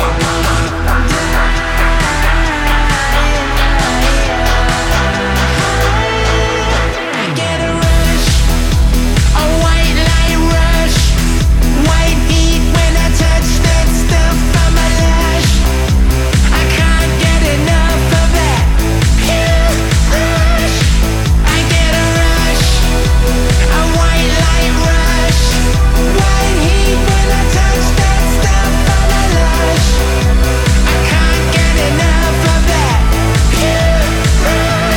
2025-06-06 Жанр: Танцевальные Длительность